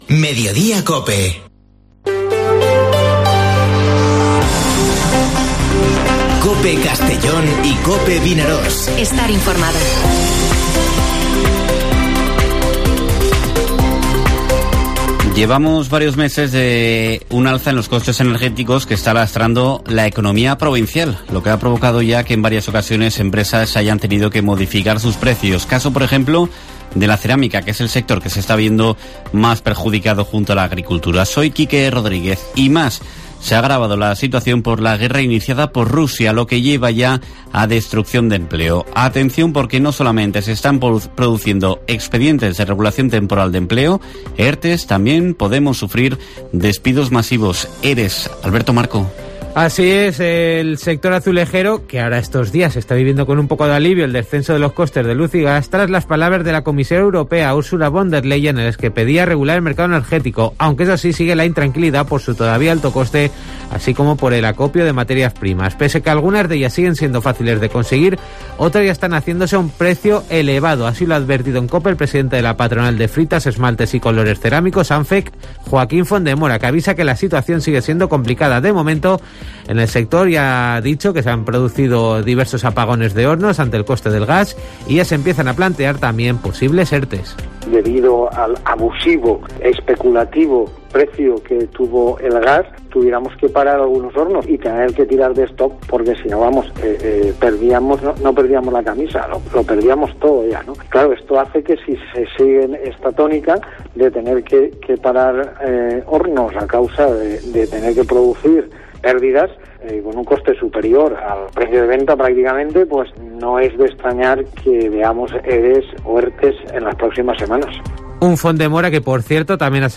Informativo Mediodía COPE en la provincia de Castellón (15/03/2022)